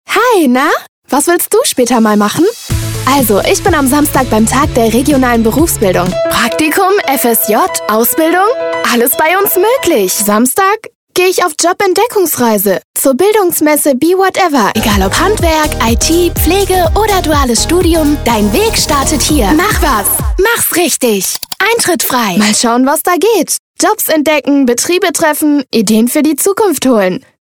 In die Arbeit vorm Mikrofon ist sie quasi hineingewachsen und wird auch heute noch mitunter als aufgeweckte junge "Kinderstimme" in der Werbung oder auch für Hörspiele oder Synchron gebucht.
Funk 2025